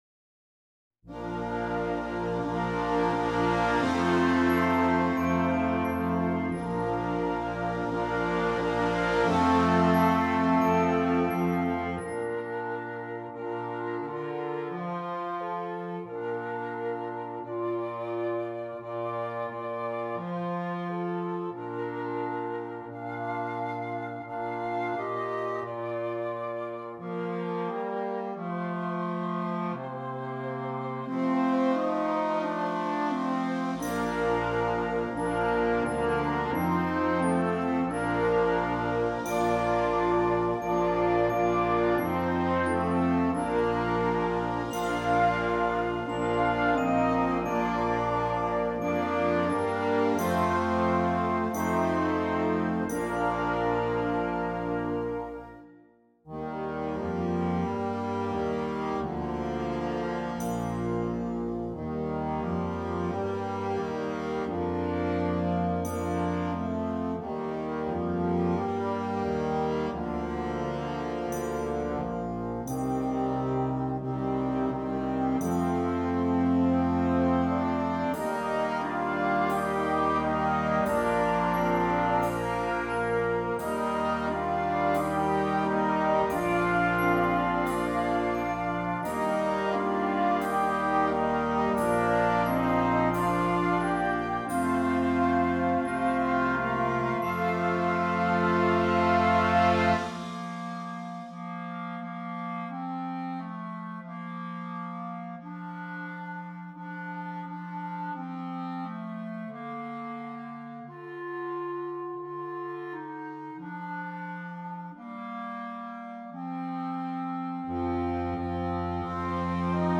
Concert Band
calming, simple, and enjoyable to perform and listen to.